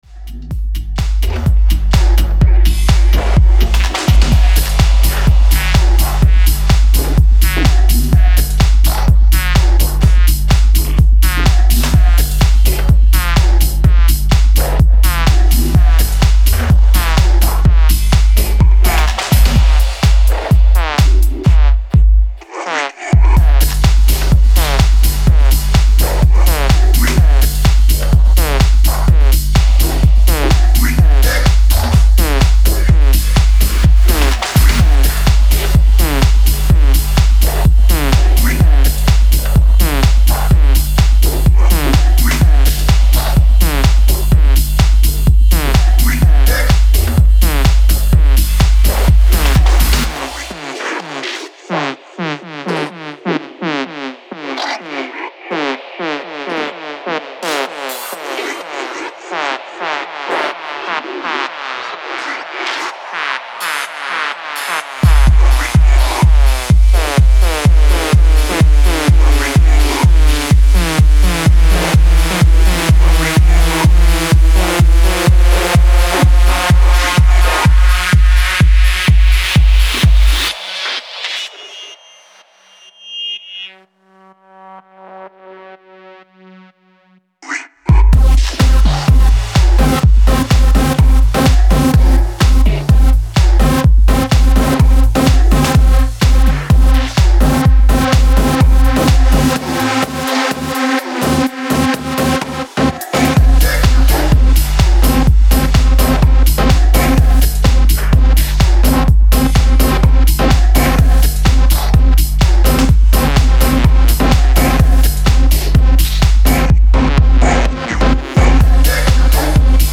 Style: Techno / Minimal